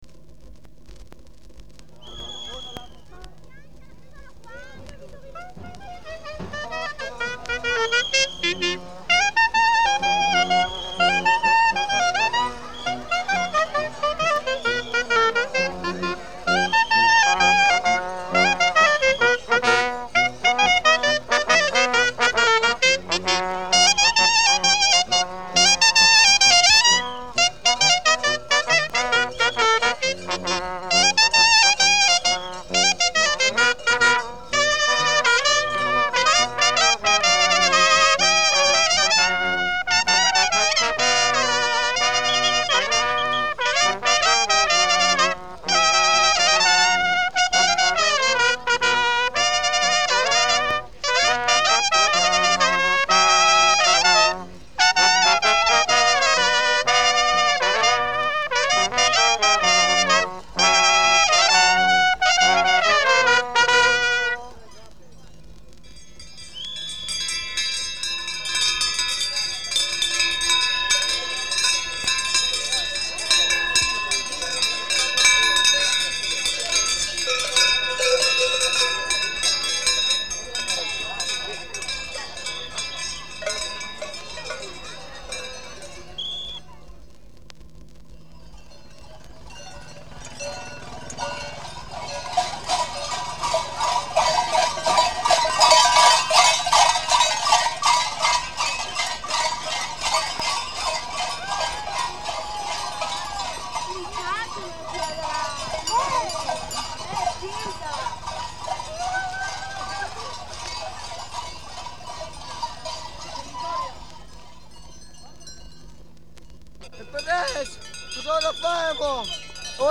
14 - Voci e musiche del carnevale di Schignano.mp3